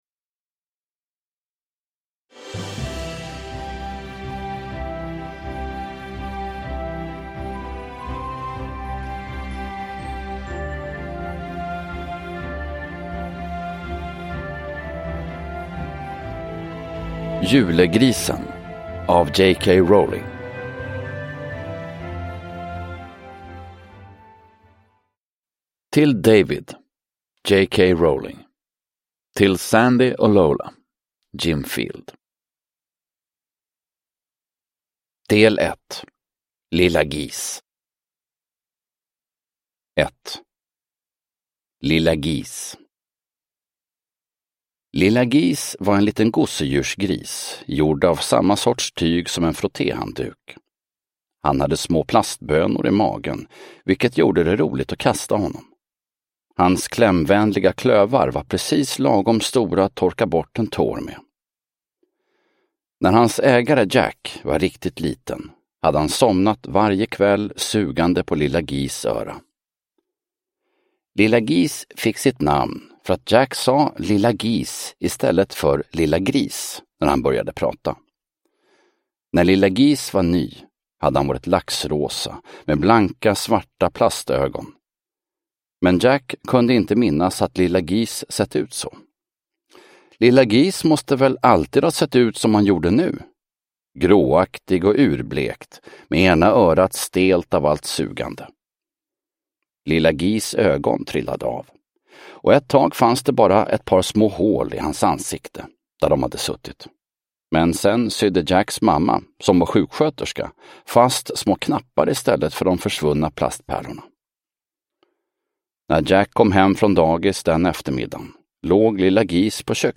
Julegrisen – Ljudbok – Laddas ner
Uppläsare: Fredde Granberg